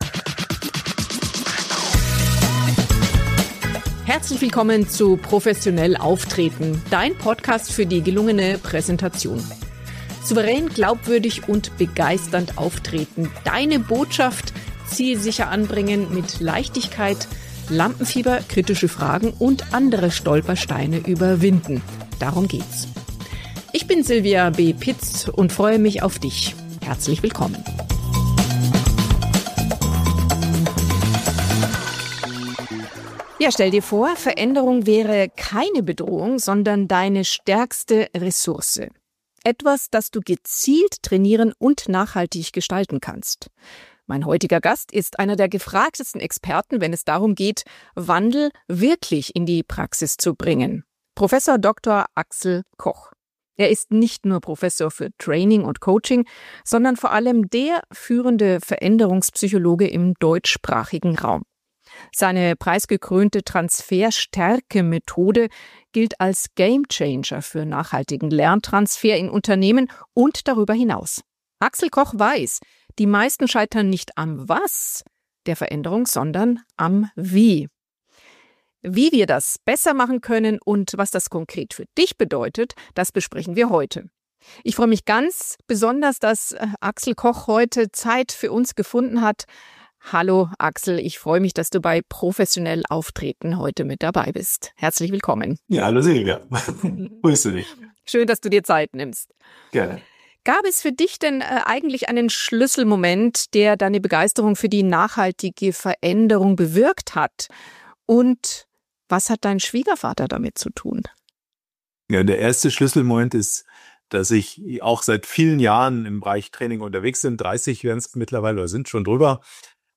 Ein fundiertes, praxisnahes Gespräch für alle, die nicht nur gut wirken wollen – sondern nachhaltig professionell auftreten möchten.